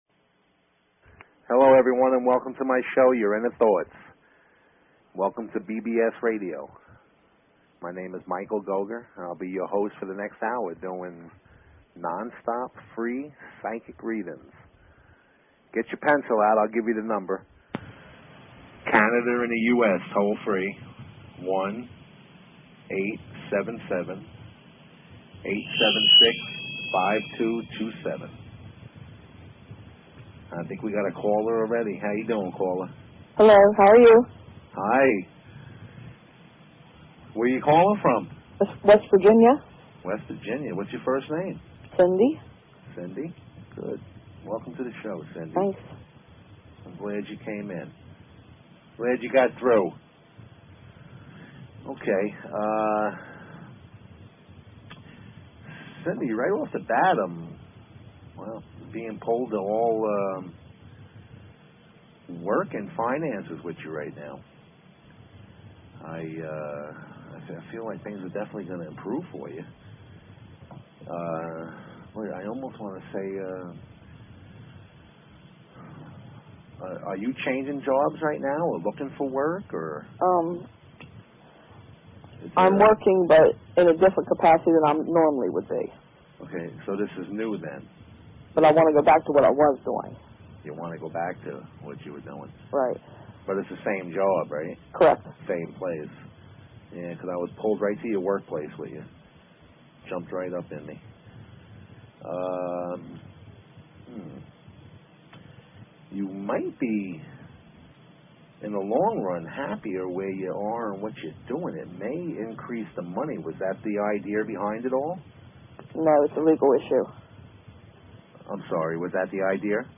Talk Show Episode, Audio Podcast, Your_Inner_Thoughts and Courtesy of BBS Radio on , show guests , about , categorized as